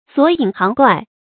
索隱行怪 注音： ㄙㄨㄛˇ ㄧㄣˇ ㄒㄧㄥˊ ㄍㄨㄞˋ 讀音讀法： 意思解釋： 索：探索；隱：隱暗的事；行：從事；怪：怪事。